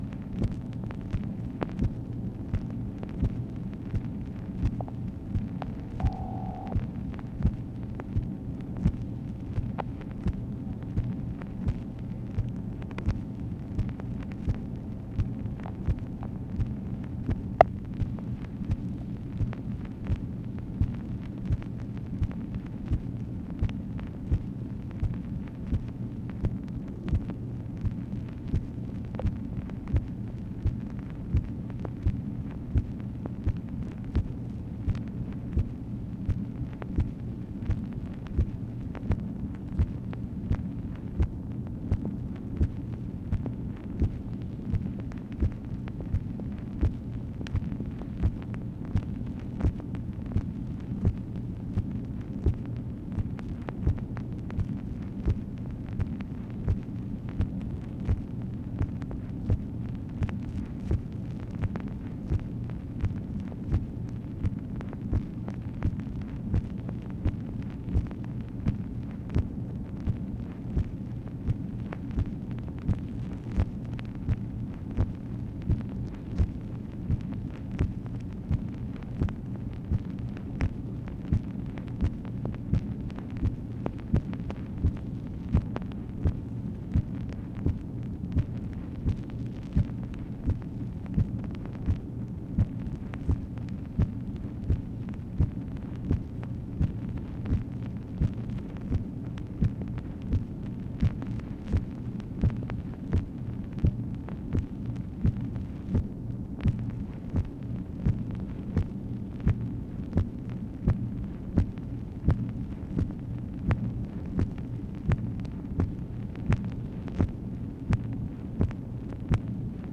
MACHINE NOISE, March 2, 1966